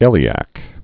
(ĭlē-ăk)